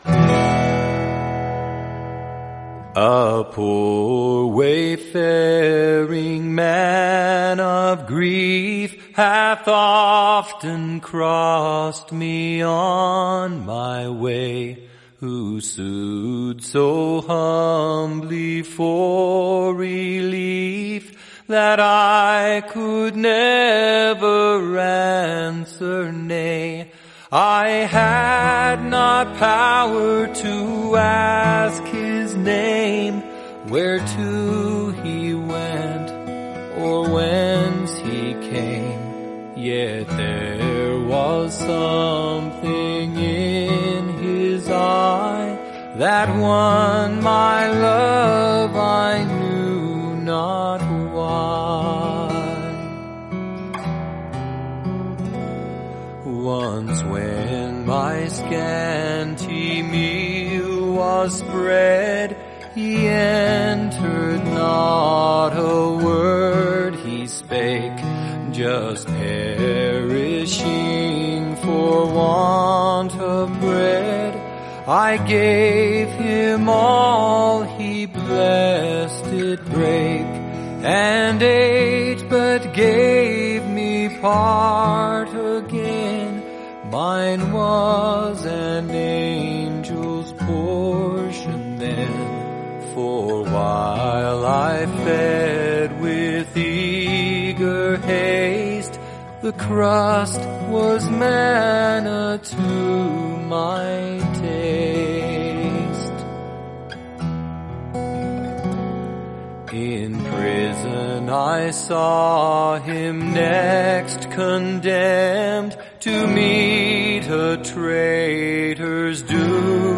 Love in action is demonstrated in the beautiful hymn